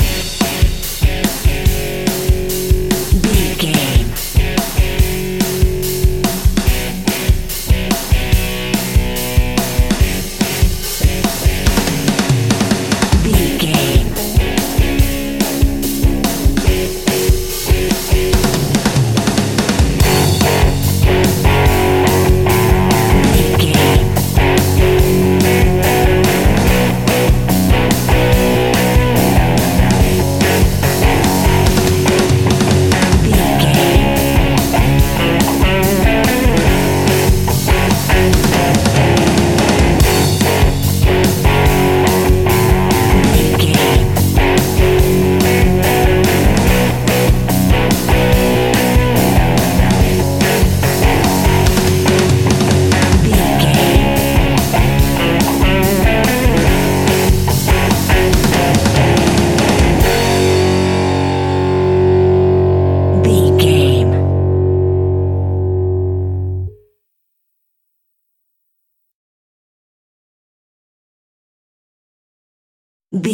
royalty free music
Epic / Action
Fast paced
Ionian/Major
F#
hard rock
blues rock
instrumentals
Rock Bass
Rock Drums
distorted guitars
hammond organ